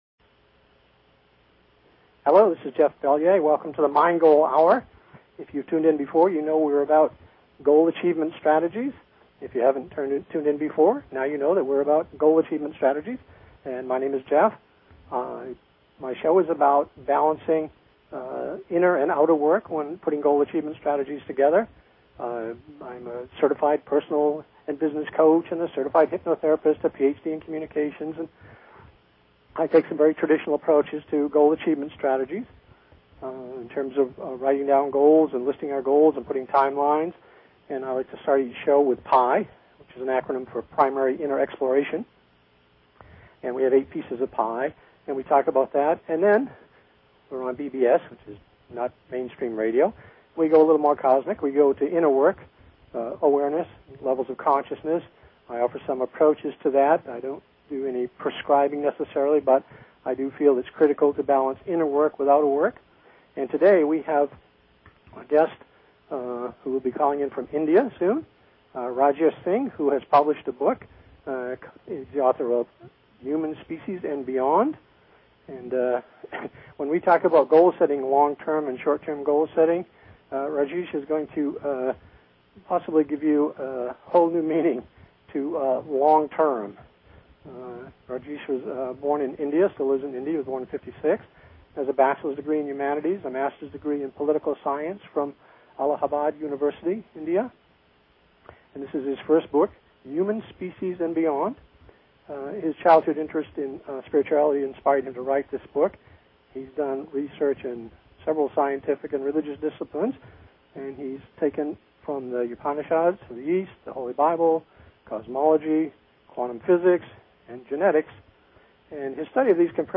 Talk Show Episode, Audio Podcast, Mind_Goal and Courtesy of BBS Radio on , show guests , about , categorized as